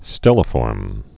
(stĕlə-fôrm)